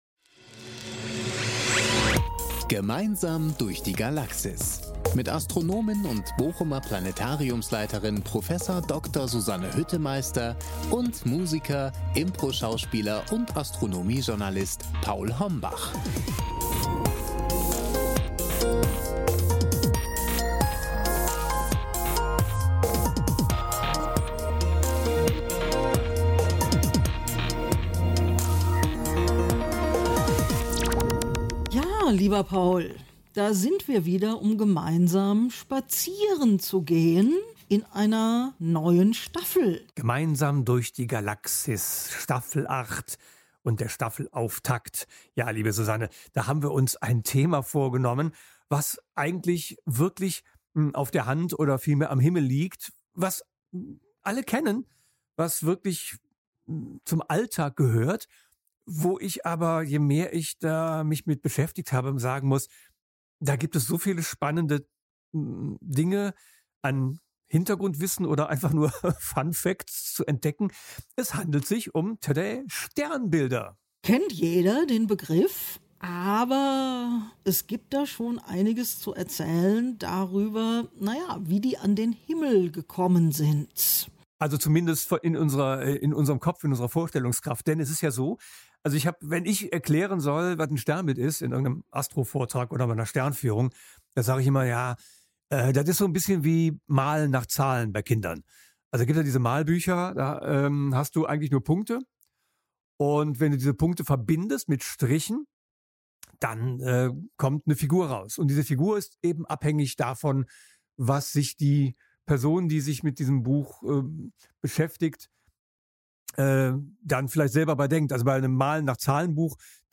Astronomische Plaudereien aus dem Planetarium Bochum